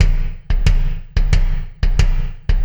Kick Particle 10.wav